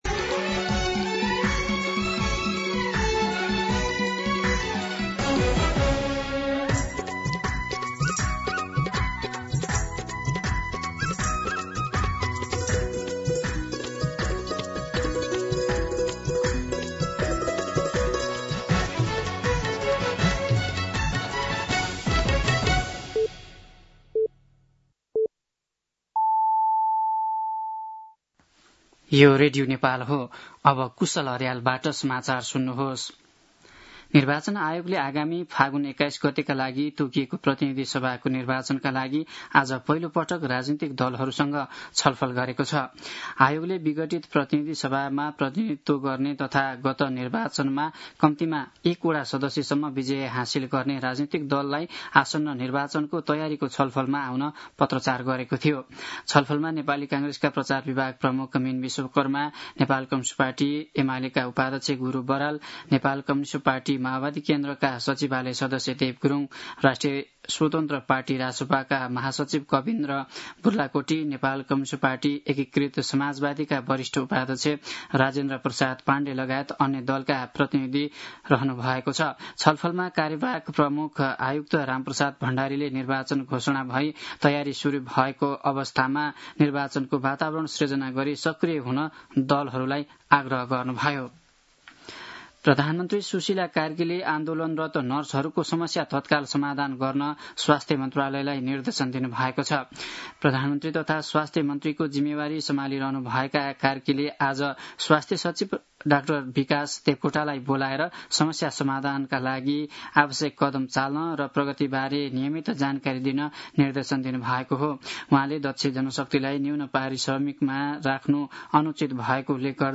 दिउँसो ४ बजेको नेपाली समाचार : ३० असोज , २०८२
4-pm-Nepali-News-6.mp3